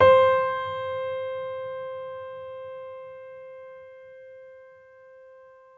piano-sounds-dev
Vintage_Upright
c4.mp3